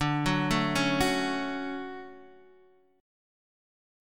DM7 chord